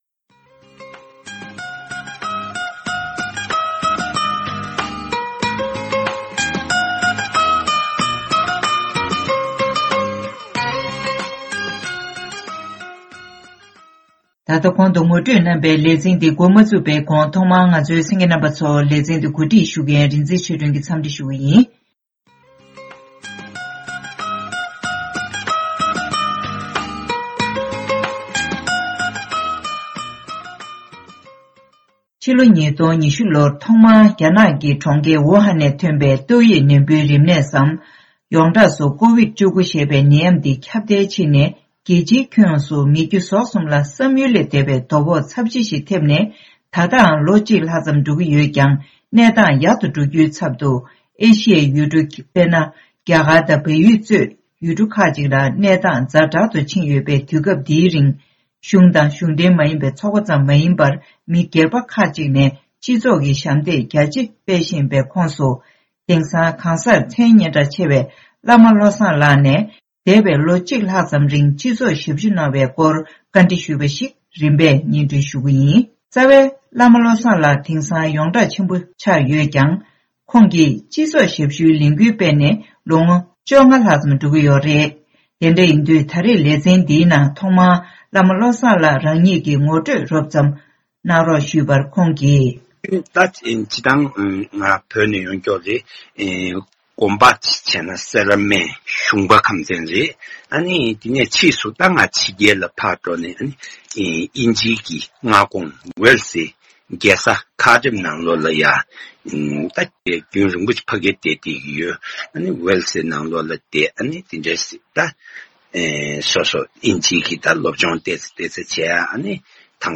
སྒྲ་ལྡན་གསར་འགྱུར།
བཀའ་འདྲི་ཞུས་ཏེ་ཕྱོགས་བསྒྲིགས་ཞུས་པ་ཞིག་གཤམ་ལ་སྙན་སྒྲོན་ཞུས་གནང་གི་རེད།